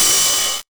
RIDE 3.wav